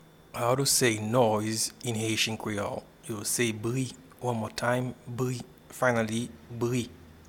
Pronunciation and Transcript:
Noise-in-Haitian-Creole-Bri.mp3